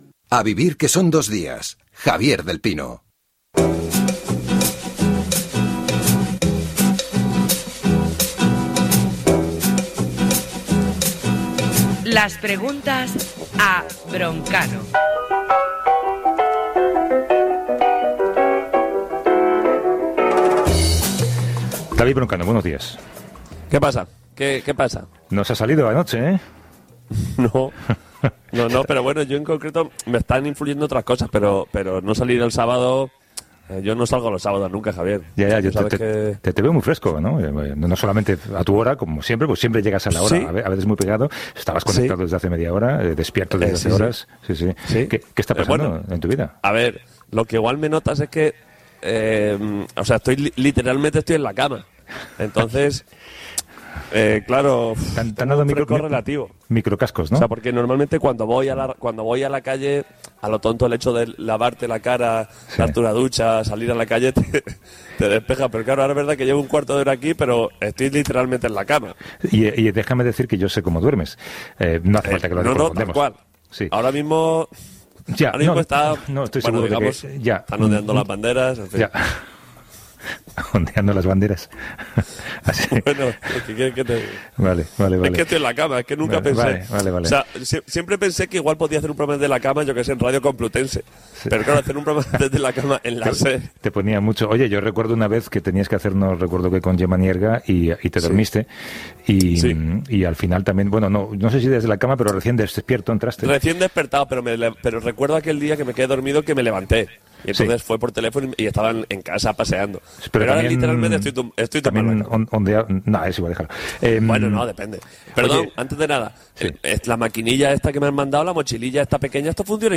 Indicatiu de la ràdio, careta de la secció, explicació de com està vivint el confinament David Broncano.
Gènere radiofònic Entreteniment